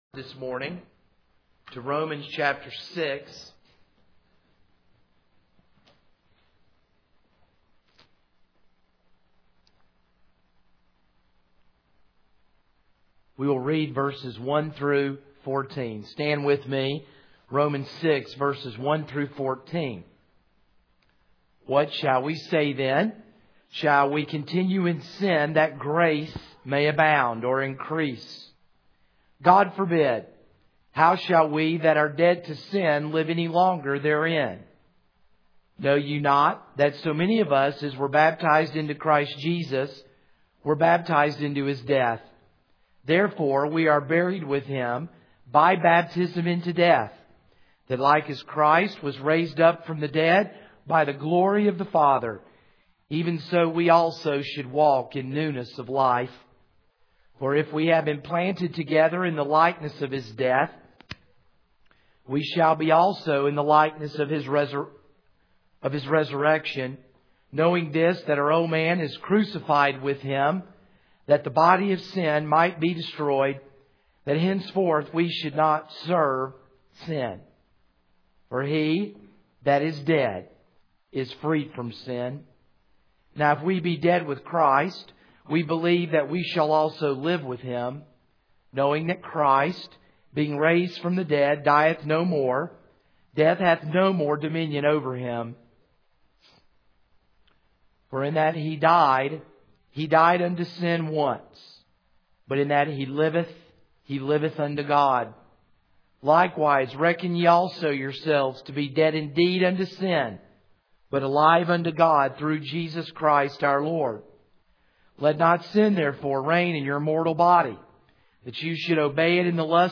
This is a sermon on Proverbs 19:8-23.